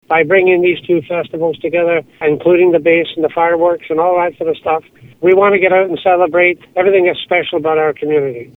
The Mayor says the town plans to combine Civic Centre Days with Canada Day celebrations into one large festival.